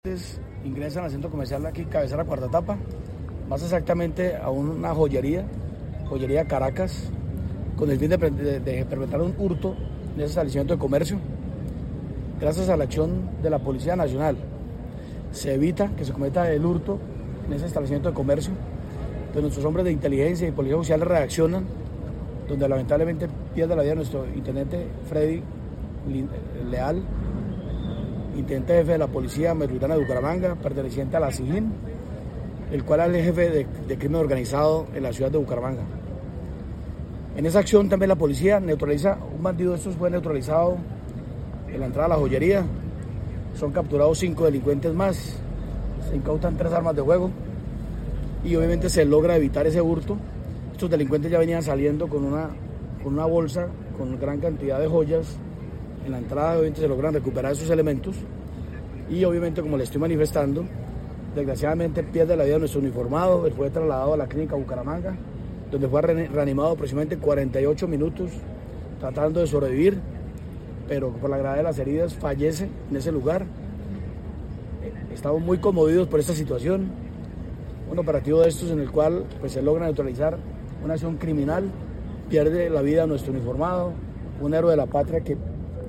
William Quintero Salazar, Comandante de la Policía Metropolitana de Bucaramanga